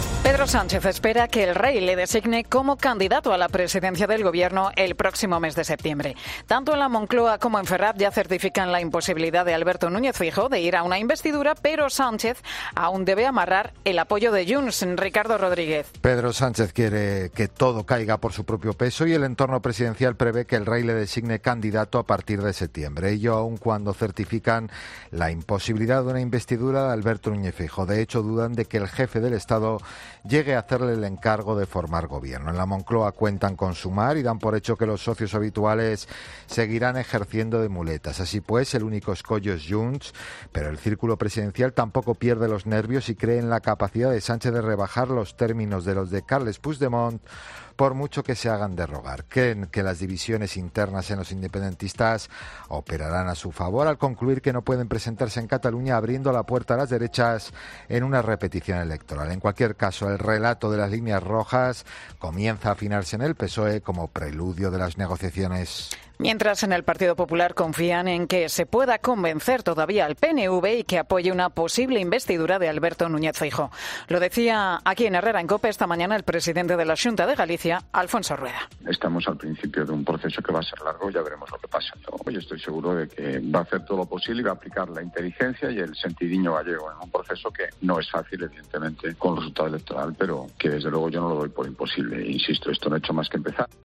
explica la atmósfera política tras el 23J